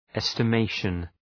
Shkrimi fonetik {,estə’meıʃən}